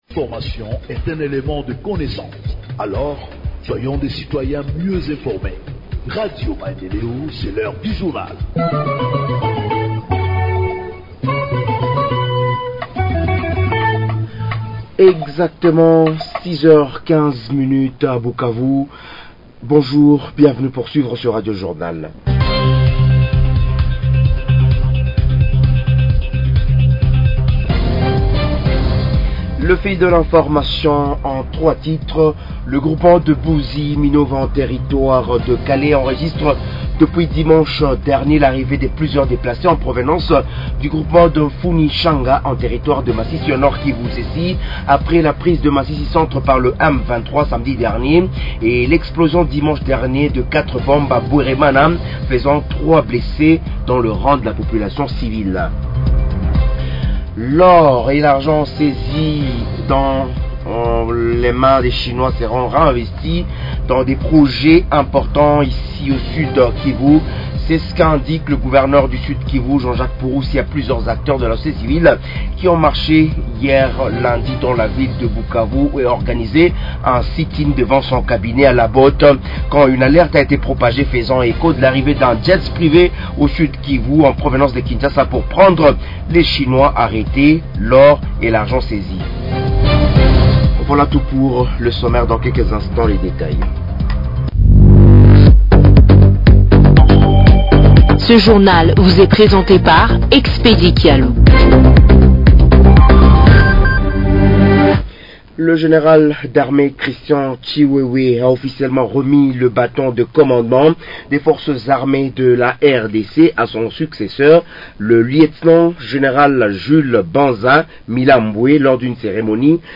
Journal Français du mardi 7 janvier 2025 – Radio Maendeleo